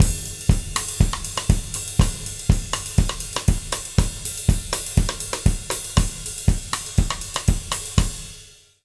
120ZOUK 09-L.wav